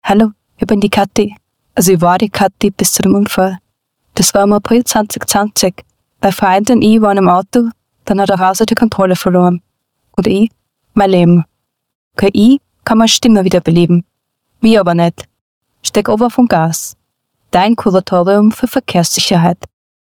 Diese lassen nun auch in einem Radiospot die getöteten Unfallopfer mittels KI-Sprach-Software selbst wieder zu Wort kommen.
KFV-Radiospot: KI kann Stimmen wieder zum Leben erwecken. Den Menschen nicht.
Mittels KI-Sprachsoftware kommt das Unfallopfer selbst zu Wort. Die Botschaft ist unmissverständlich: „Künstliche Intelligenz kann heute meine Stimme zum Leben erwecken. Mich aber nicht. Steig runter vom Gas!“.
Radiospot_cKFV.mp3